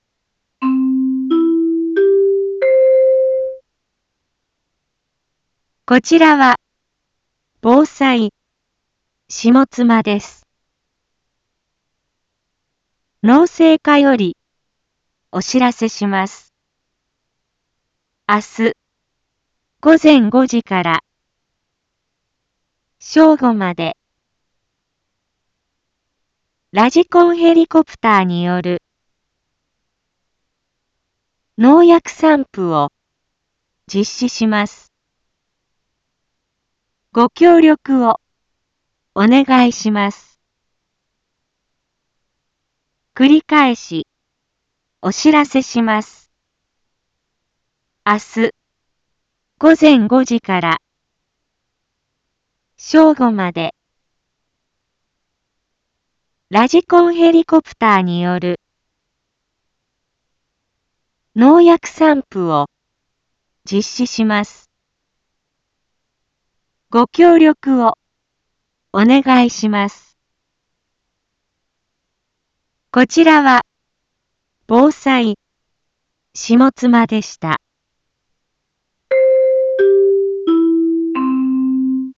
一般放送情報
Back Home 一般放送情報 音声放送 再生 一般放送情報 登録日時：2021-05-01 12:31:23 タイトル：麦のﾗｼﾞｺﾝﾍﾘによる防除（上妻） インフォメーション：こちらは防災下妻です。